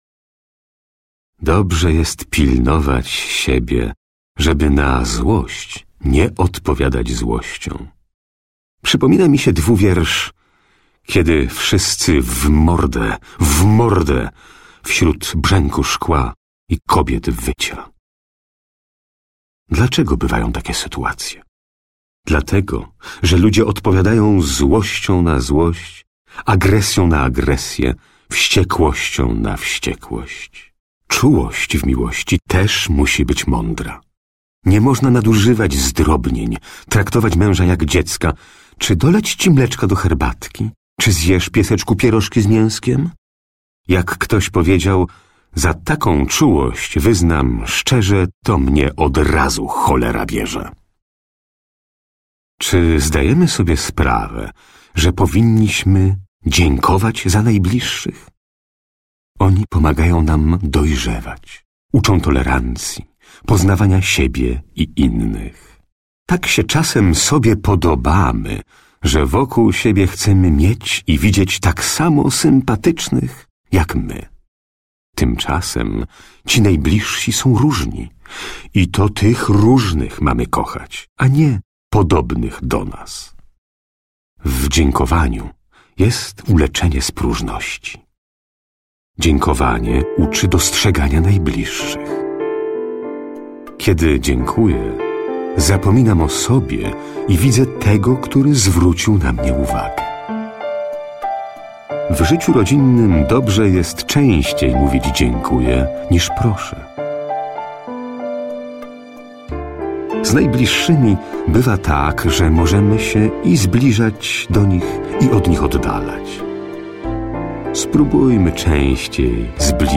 Male 30-50 lat
Nagranie lektorskie